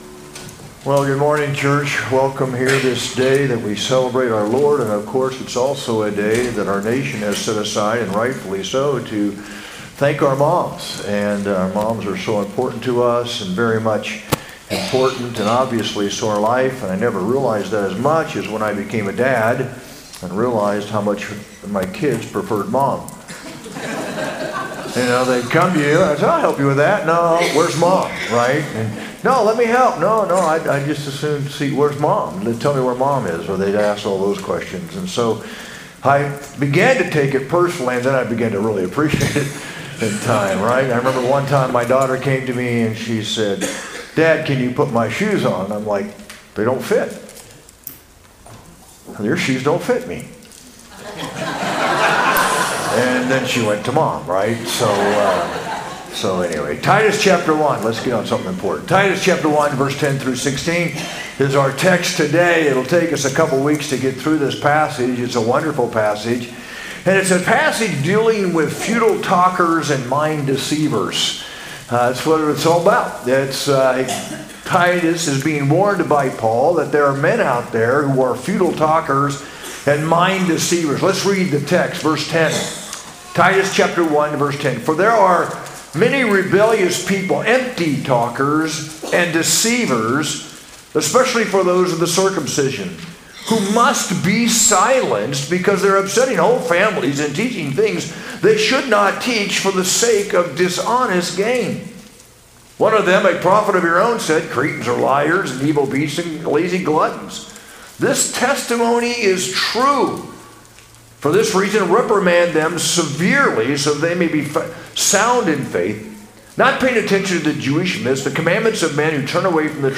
sermon-5-11-25.mp3